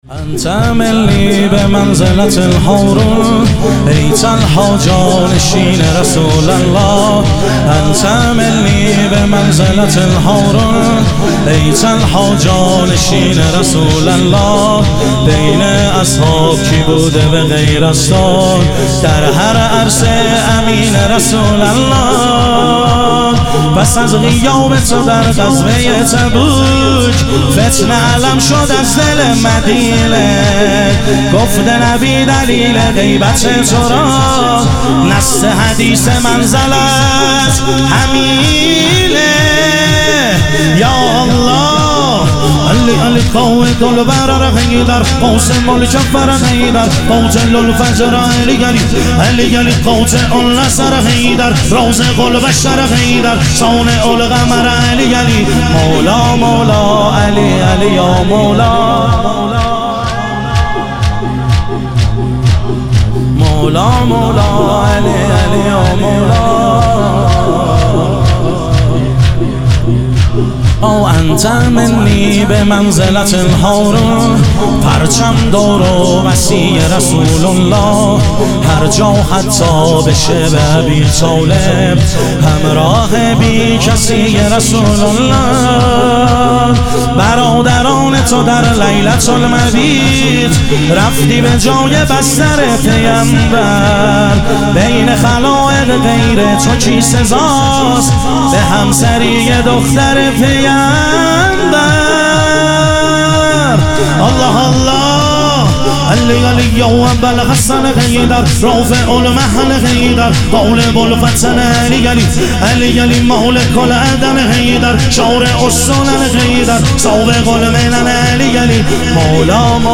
شهادت حضرت سلطانعلی علیه السلام - شور